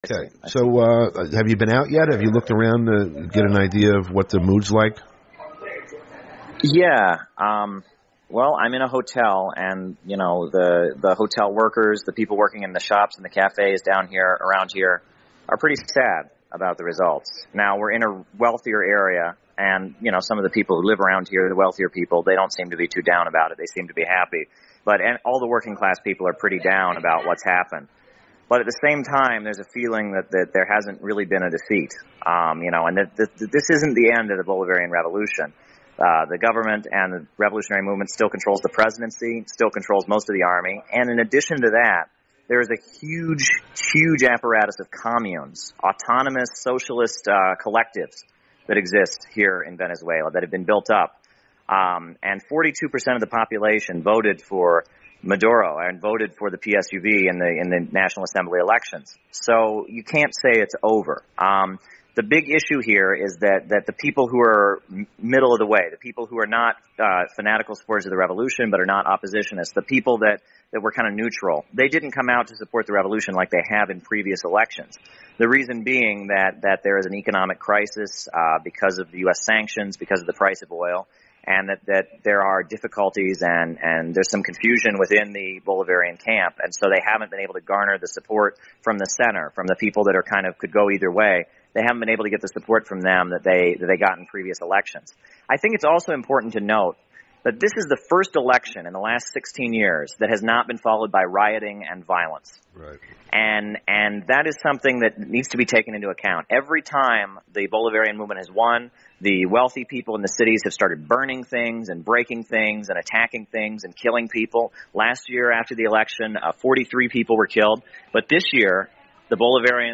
Program Type: Interview Speakers